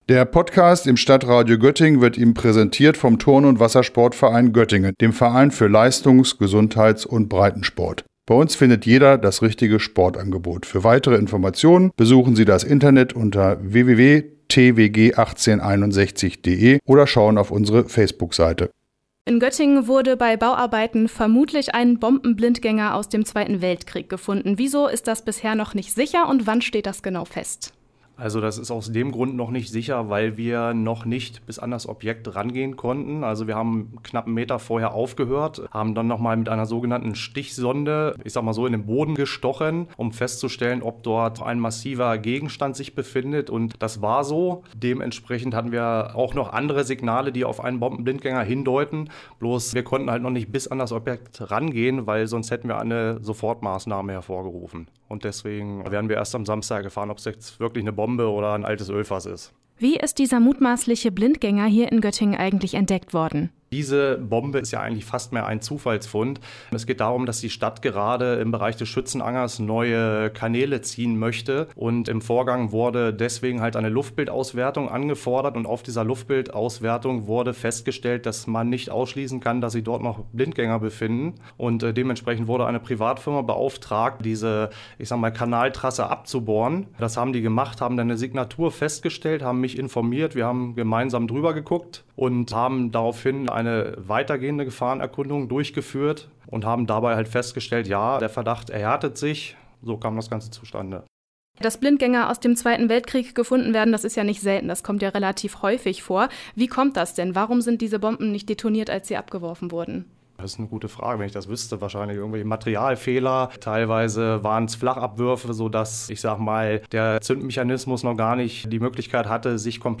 Im StadtRadio-Interview